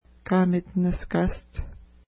ID: 497 Longitude: -60.8611 Latitude: 53.2399 Pronunciation: ka:mətnəʃka:st Translation: Muddy Place (small) Feature: point Explanation: This point, located at the mouth of a small river, is very muddy.